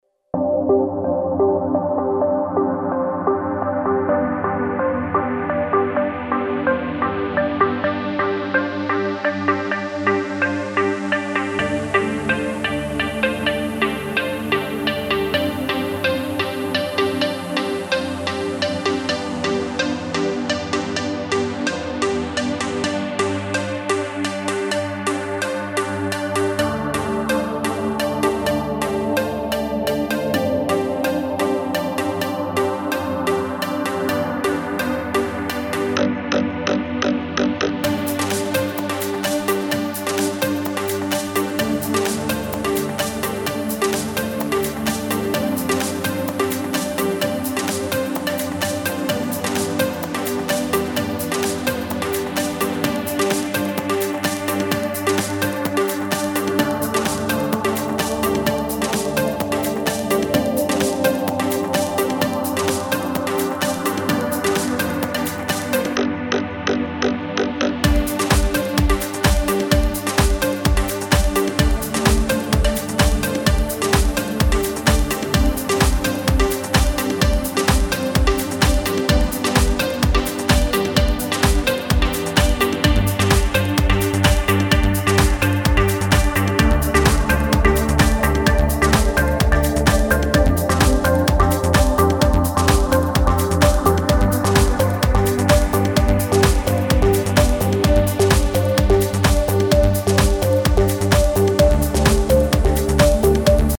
Progressive House